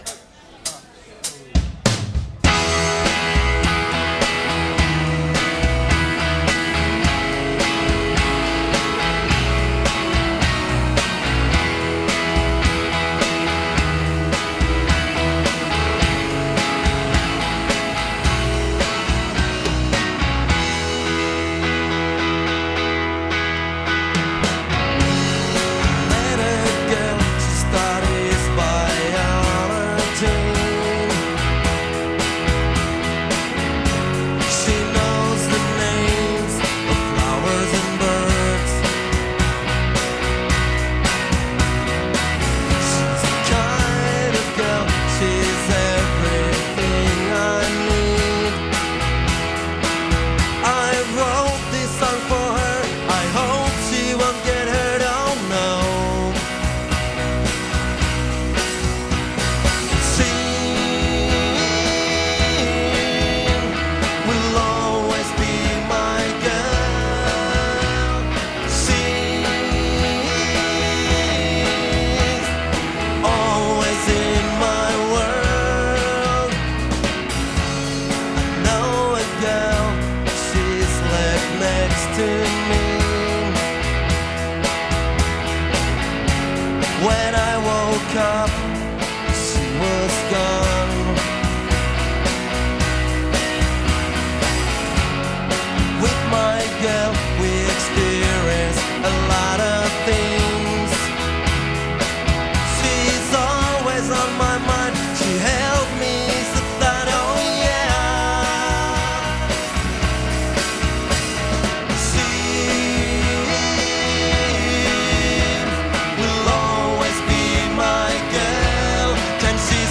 Turun Klubin keikka oli ja meni hyvin.